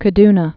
(kə-dnə)